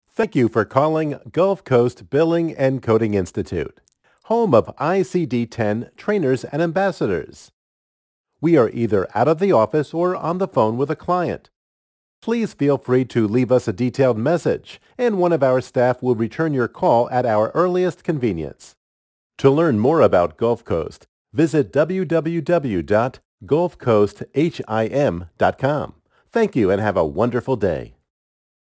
Auto-Attendant Builder - Aliciainc Consulting-Human Resources and Technology Solutions
Sample Voices
Male Voice #1